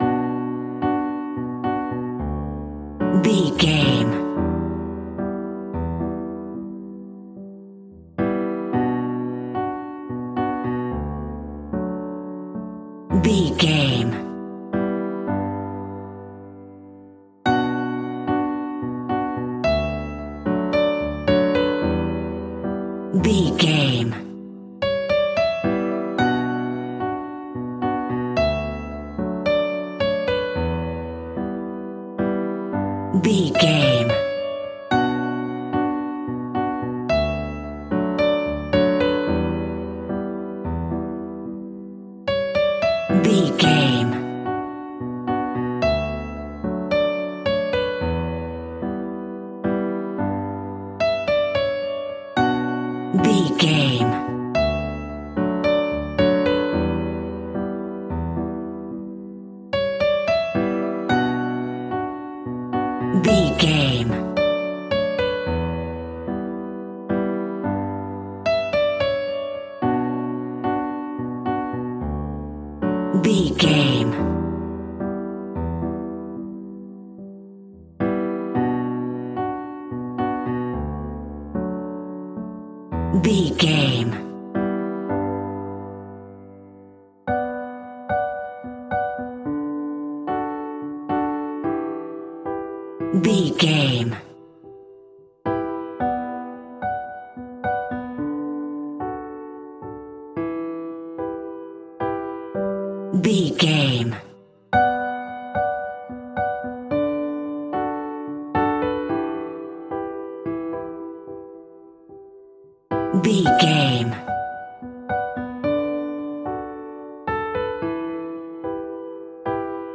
Ionian/Major
Slow
light
relaxed
tranquil
synthesiser
drum machine